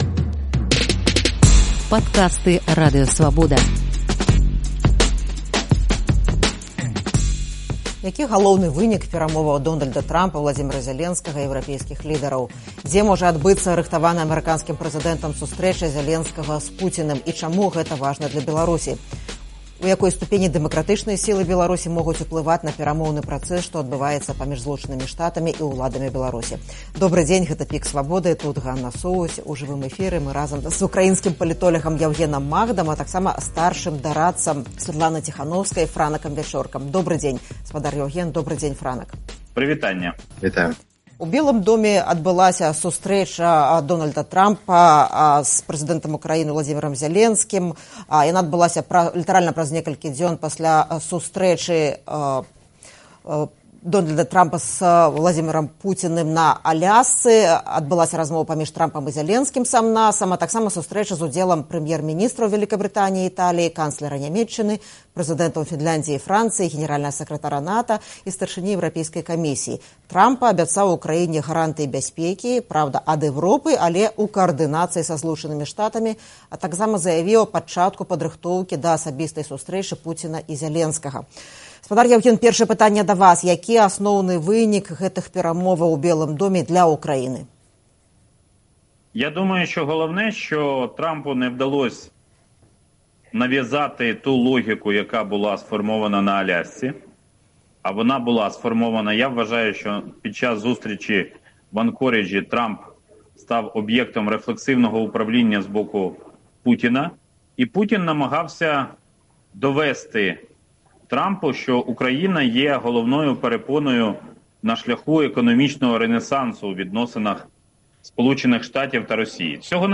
У жывым эфіры «ПіКа Свабоды»